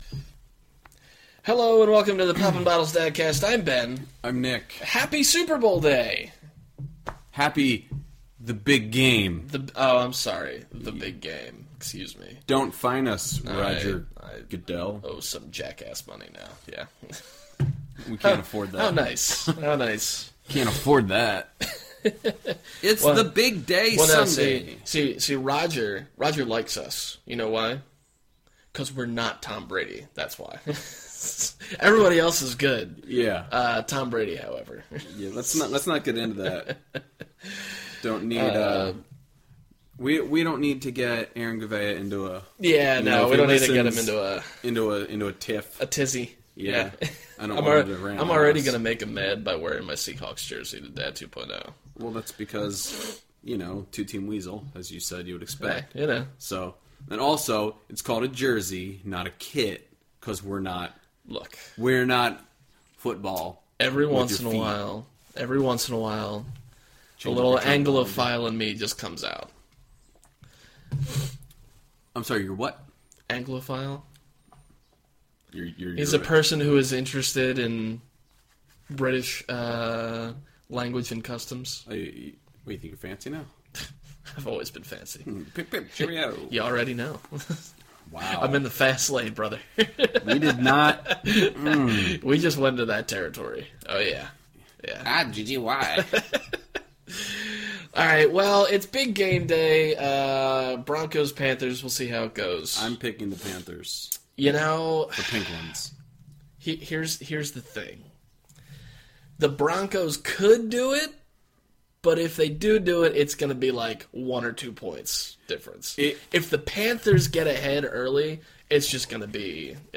This week is a deep episode as we discuss depression both in ourselves and others. It starts off a bit jolly while we talk about the Super Bowl, but the recent news about Johnny Manziel has sparked a larger conversation.